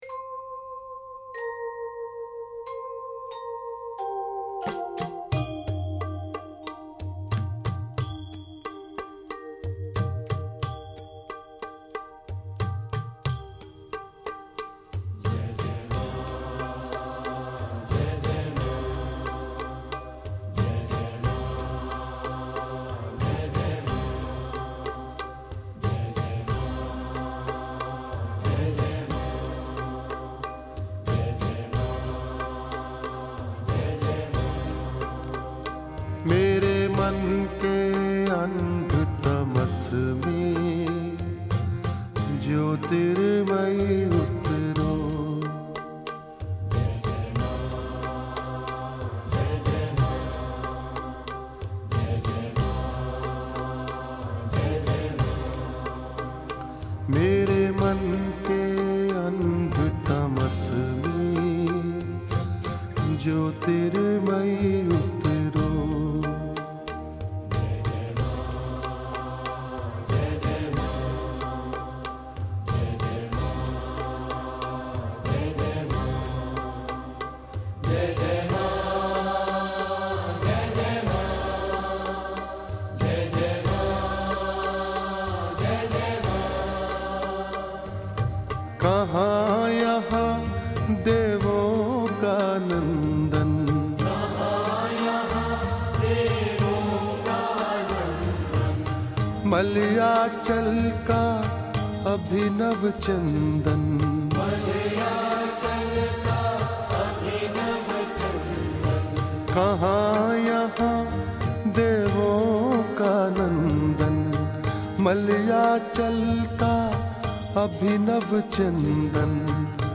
Bhajans: